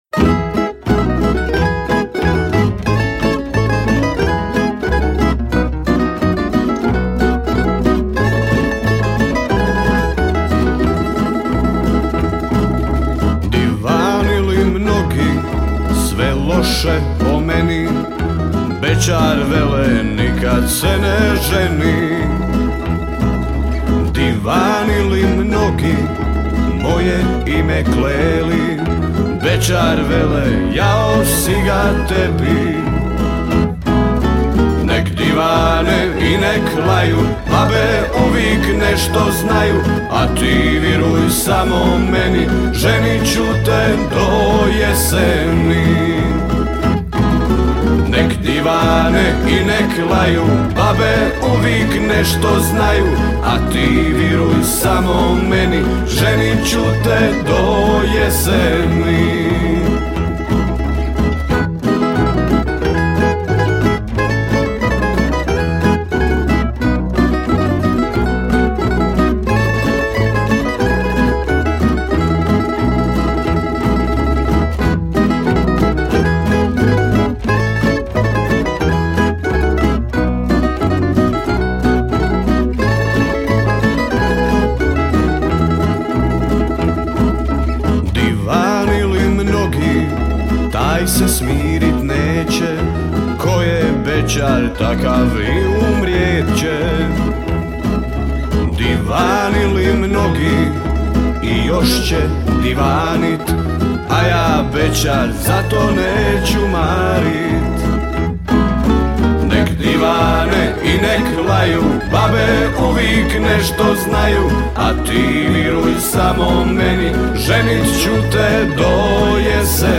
Festival pjevača amatera
Zvuci tamburice odzvanjali su prepunom dvoranom vatrogasnog doma u Kaptolu do kasnih noćnih sati.